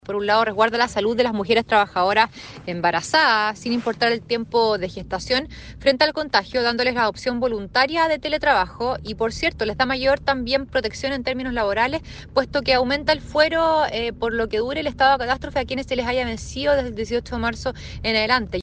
La senadora de RN, Marcela Sabat, una de las promotoras, entregó detalles del proyecto.